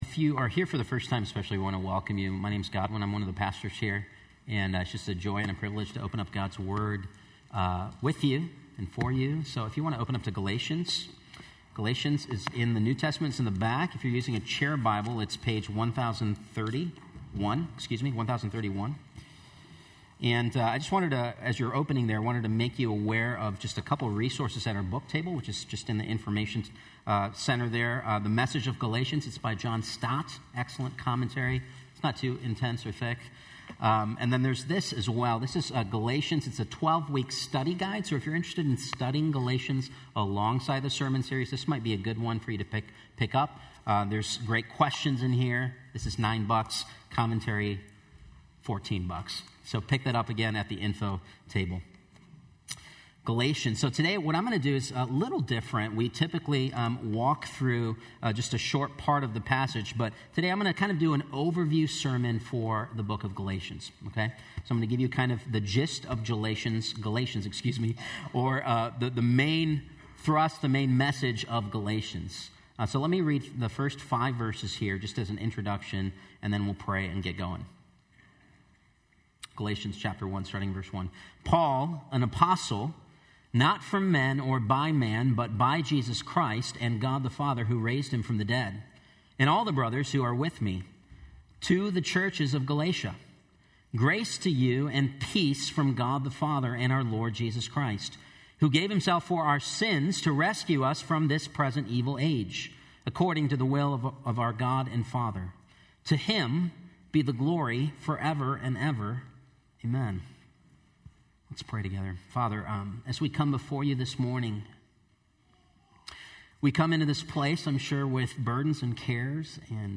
Faith Church Sermon Podcast Galatians- Freedom in Christ - Jesus + ?